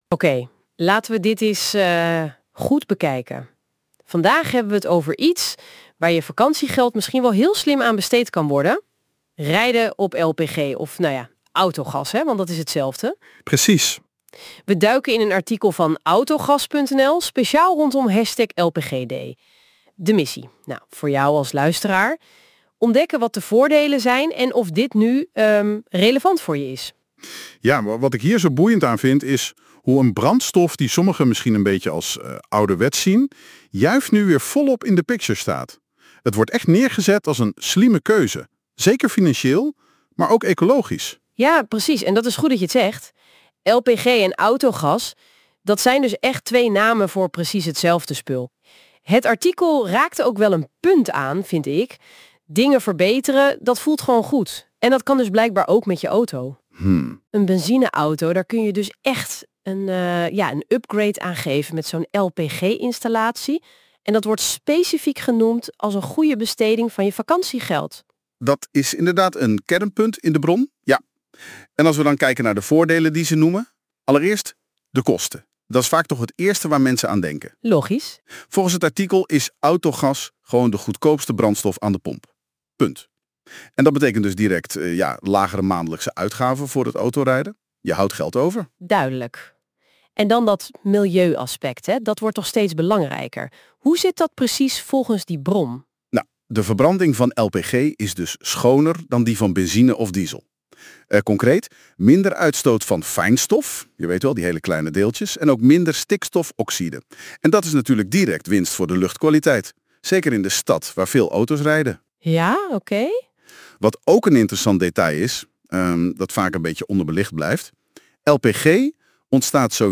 Kleine sidenote: deze aflevering is gemaakt door AI, slim hè